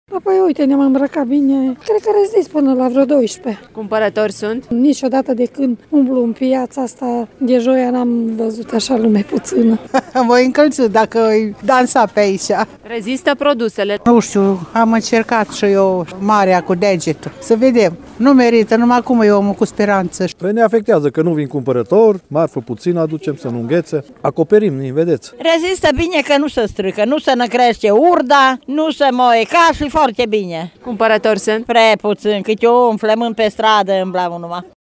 Cu tarabele mai goale decât de obicei, cu puțina marfă acoperită de materiale groase ori expusă în saci, vânzătorii speră să nu fi venit chiar degeaba în piață: